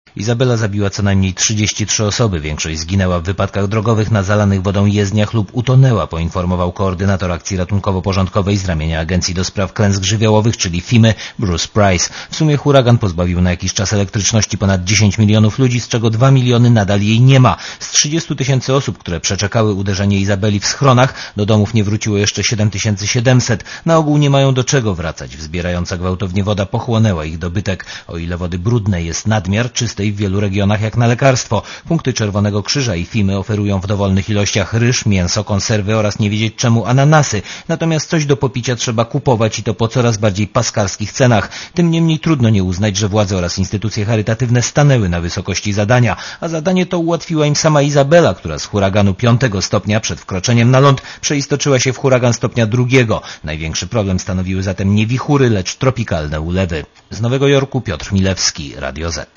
Korespondencja Radia Zet z Nowego Jorku (232Kb)